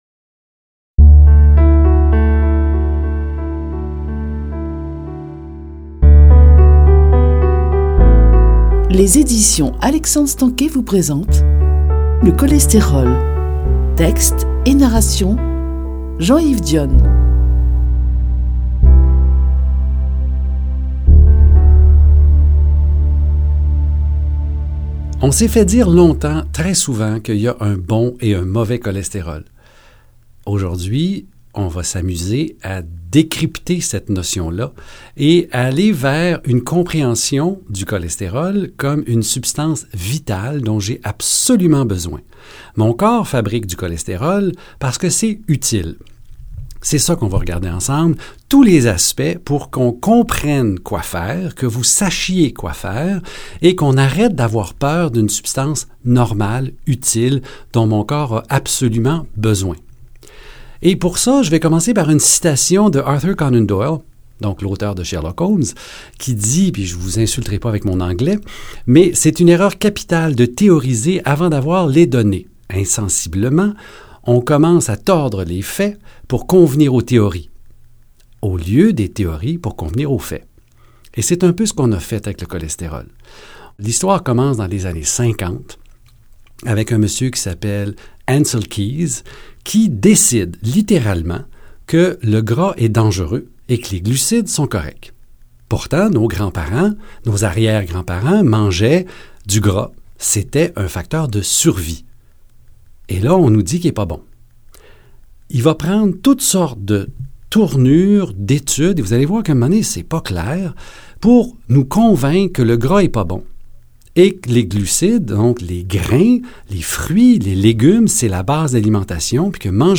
Dans ce livre audio, nous verrons d’où vient cette peur du « mauvais cholestérol » et ce qu’on devrait faire à son sujet.